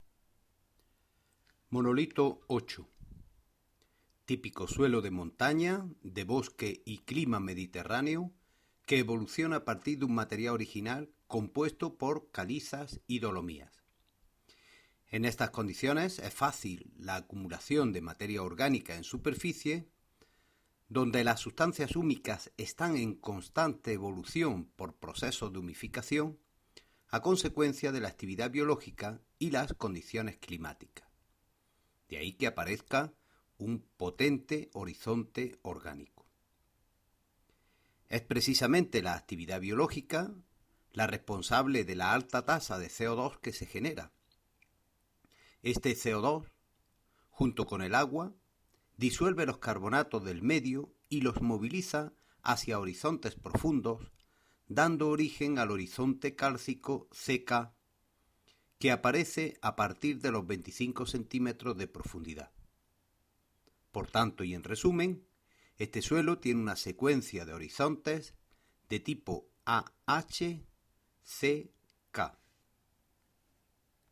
audio guía monolito 8